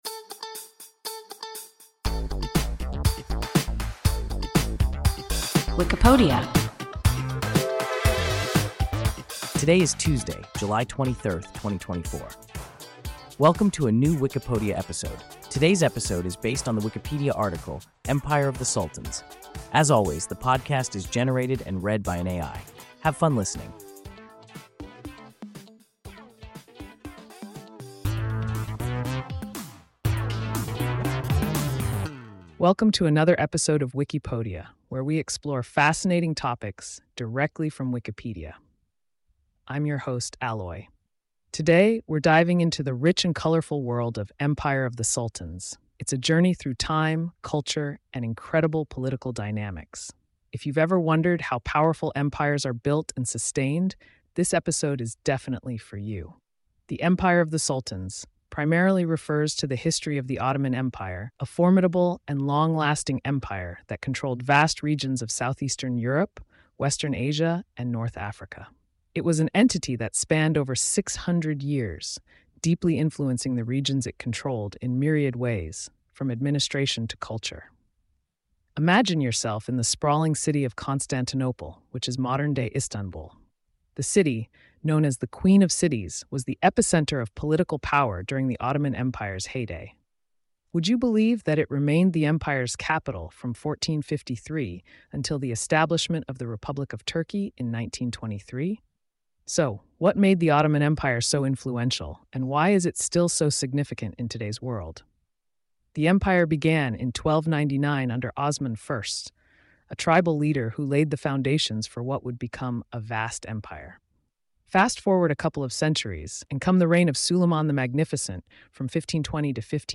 Empire of the Sultans – WIKIPODIA – ein KI Podcast